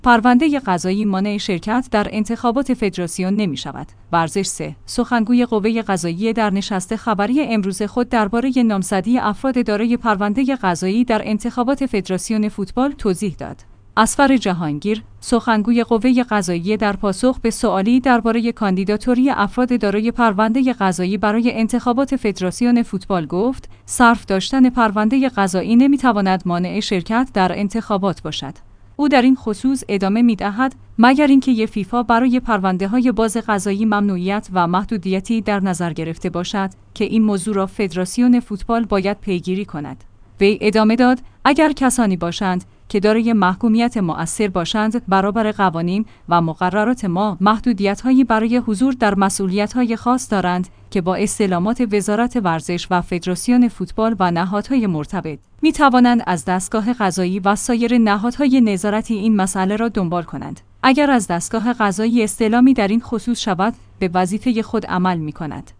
ورزش 3/سخنگوی قوه قضاییه در نشست خبری امروز خود درباره نامزدی افراد دارای پرونده قضایی در انتخابات فدراسیون فوتبال توضیح داد.